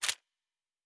pgs/Assets/Audio/Sci-Fi Sounds/Weapons/Weapon 07 Foley 1.wav at master
Weapon 07 Foley 1.wav